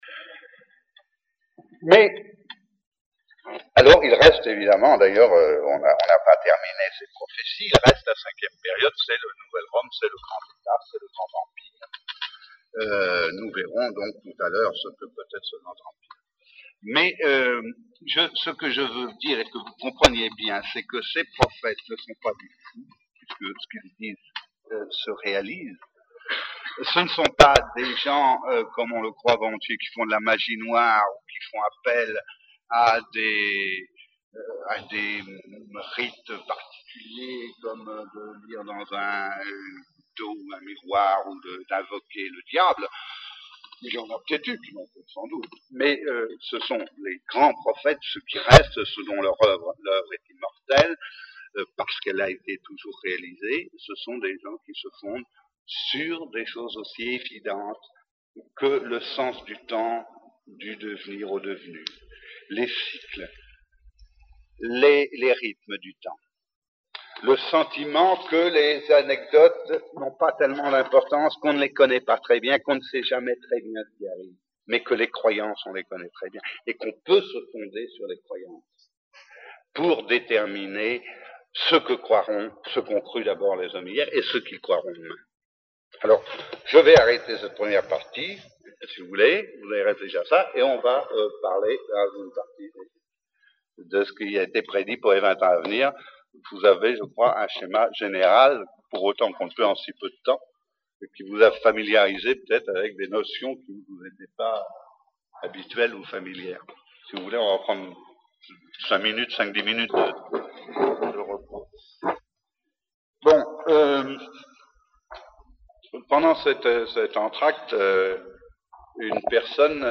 J’ai enregistré cette conférence, et vous la restitue ici.